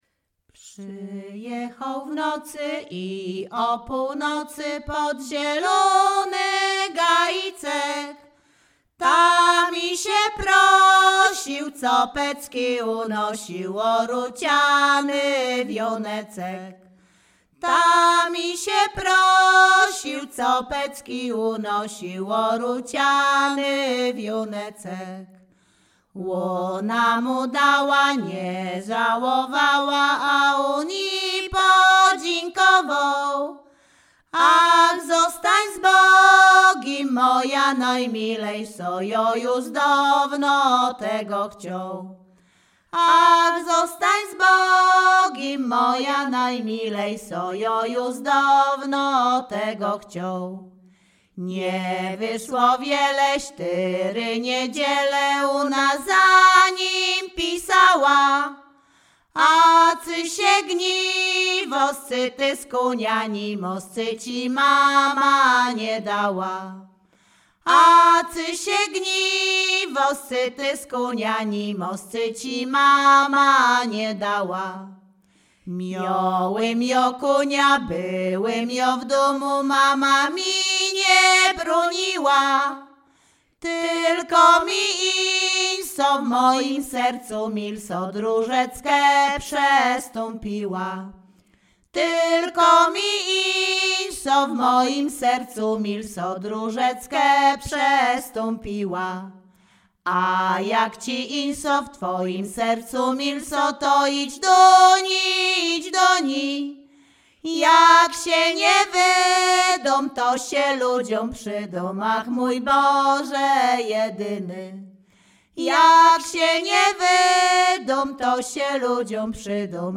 Śpiewaczki z Chojnego
Sieradzkie
liryczne miłosne